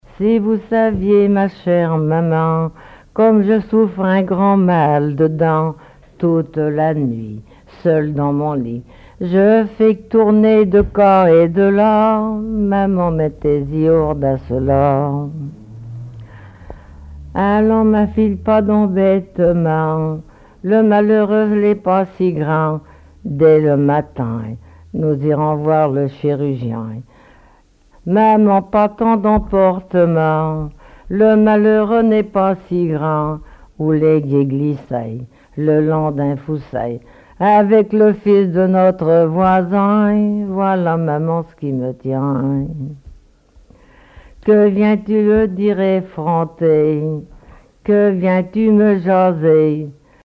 Genre dialogue
collecte en Vendée
Témoignages et chansons traditionnelles
Pièce musicale inédite